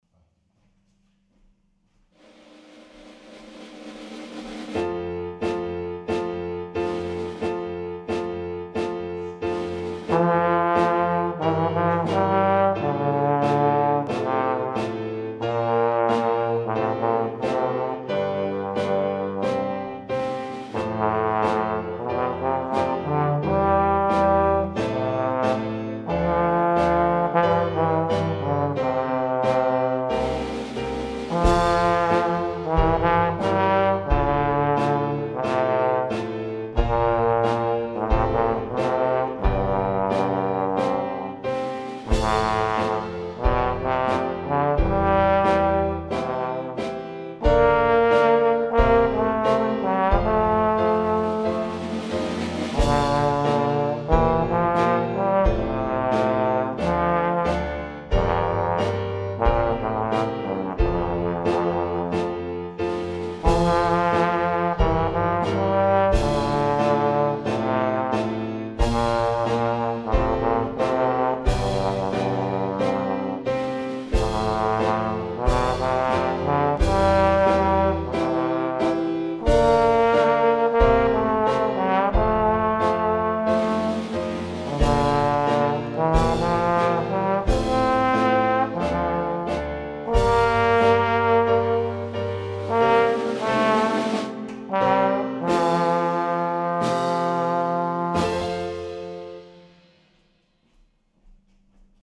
Trombone - Piano
Concerto